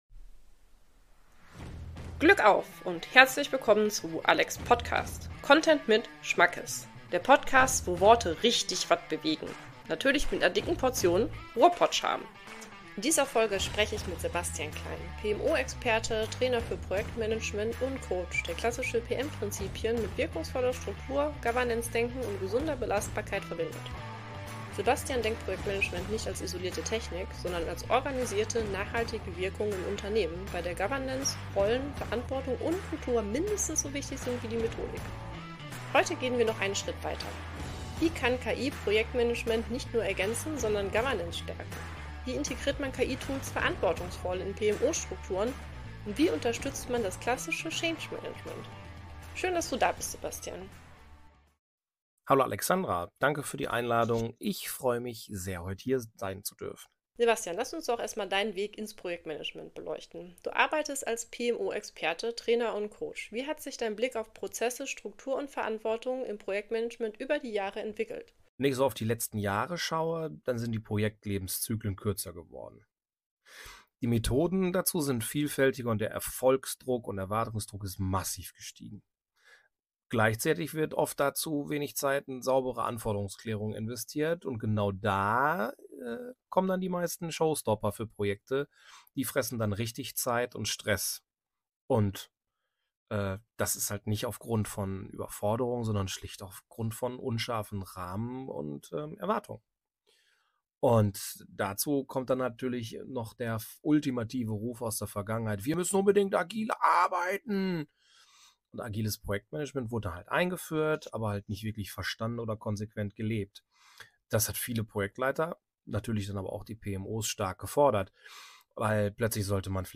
Ein Gespräch für alle, die Projektmanagement als strategisches Rückgrat sehen und die wissen wollen, wie man KI sinnvoll und zukunftsfähig integriert.